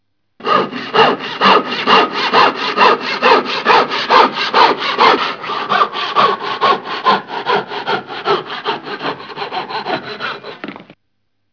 Saw
SAW.wav